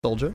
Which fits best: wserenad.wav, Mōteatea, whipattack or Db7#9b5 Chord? whipattack